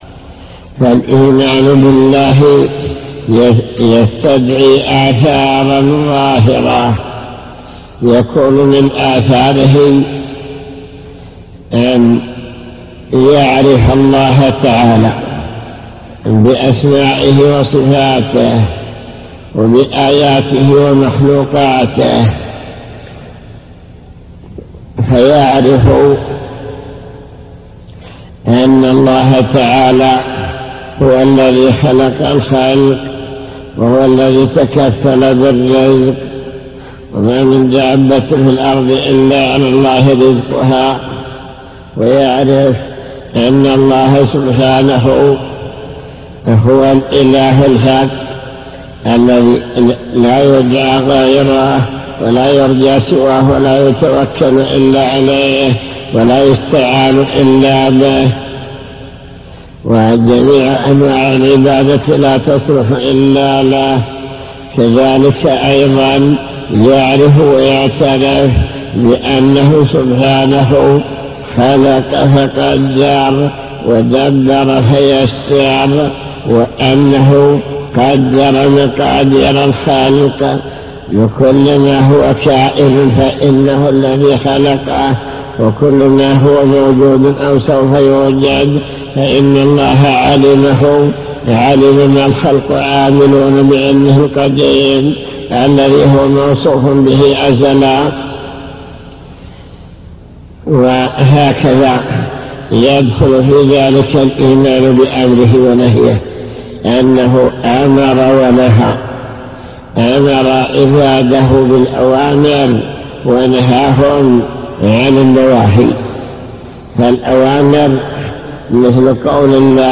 المكتبة الصوتية  تسجيلات - كتب  شرح كتاب بهجة قلوب الأبرار لابن السعدي شرح حديث قل آمنت بالله ثم استقم بشارة الله لمن آمن واستقام على طريقه